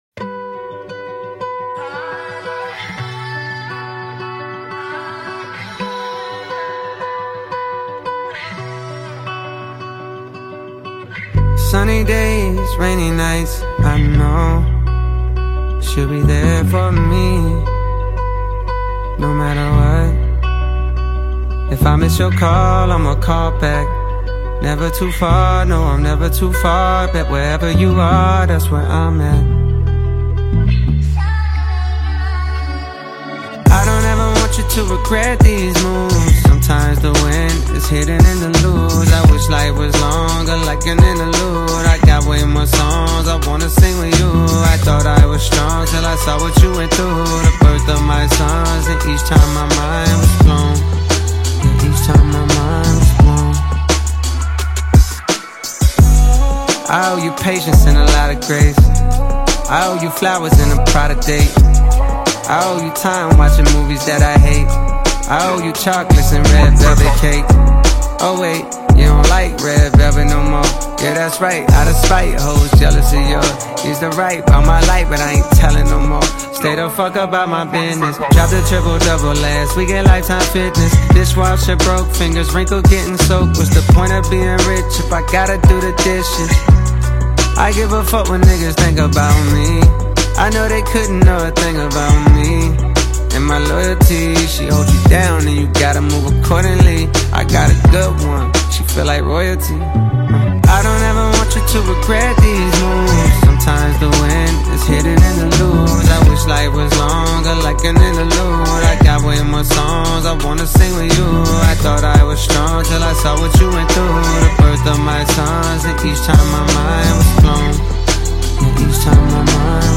Afro-fusion
melodic flows and emotionally charged delivery